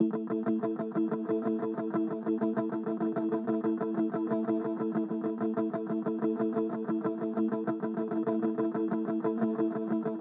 Horizon_02_94bpm_Amin
Horizon_02_94bpm_Amin.wav